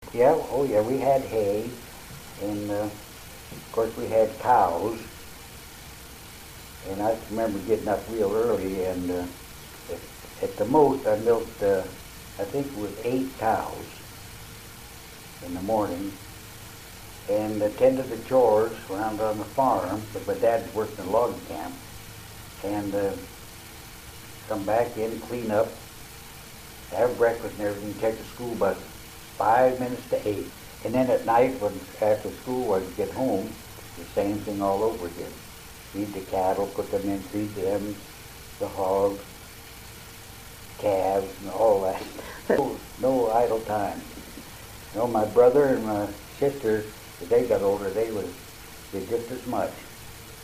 In 2001, with funding from the National Park Service Historic Preservation program, the Jamestown S’Klallam Tribe conducted interviews with Tribal Elders and transformed these oral histories into the book “Sharing Our Memories: